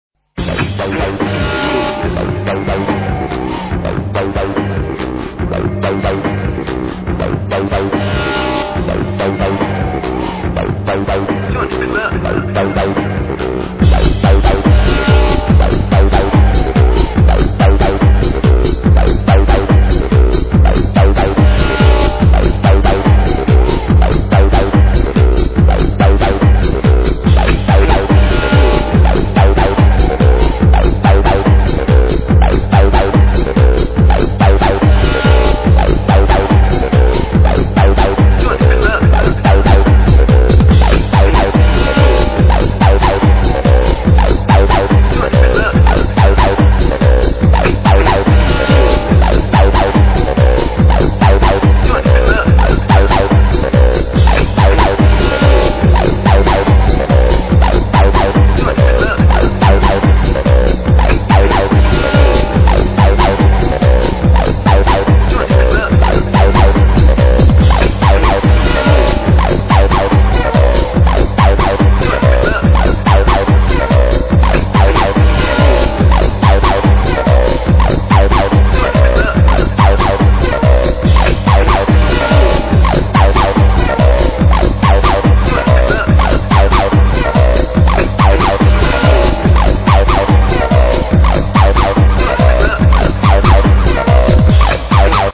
acid track (uk style as well)